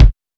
Kicks
KICK.13.NEPT.wav